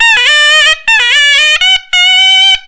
SHENNAI2  -R.wav